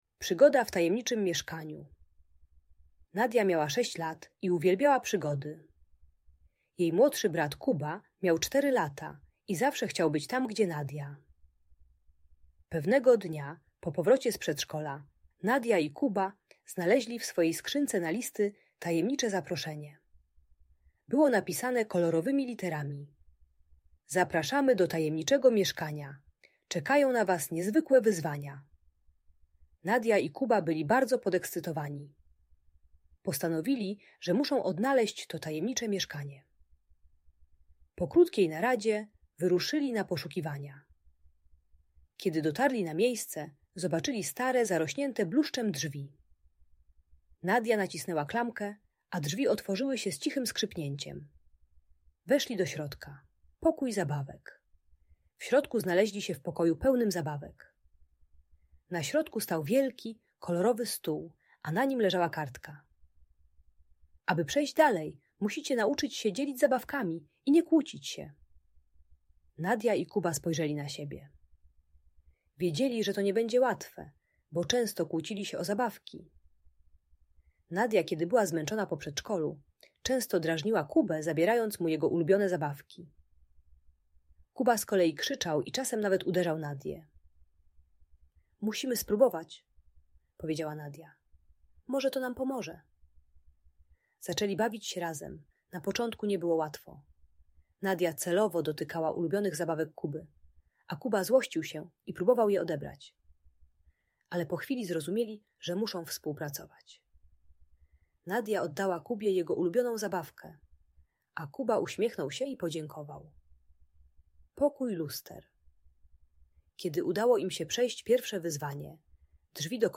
Opowieść o przygodach w tajemniczym mieszkaniu - Audiobajka